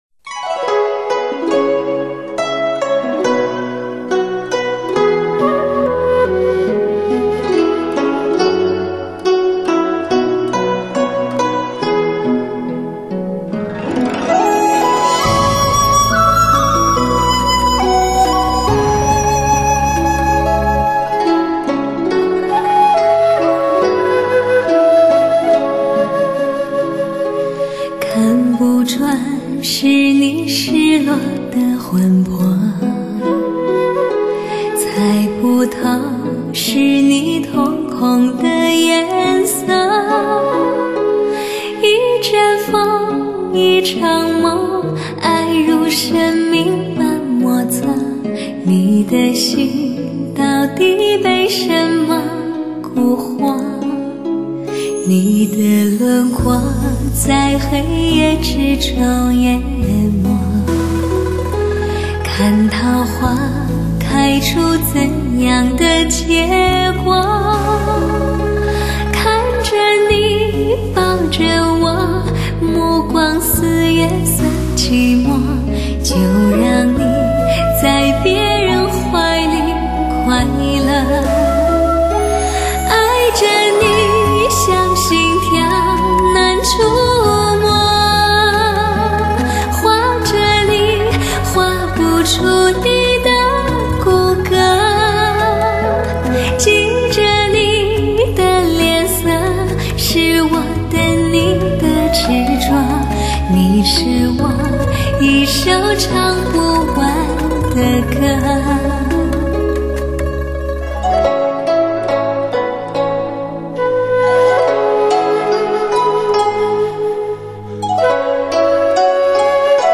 这张碟一开播已令人有很强烈的Hi-Fi气味，音乐感颇阔，空气感特佳，高音有特出的鲜明感，
音乐内容又属轻柔动听一类，人声与吉他也具水准，气氛良好。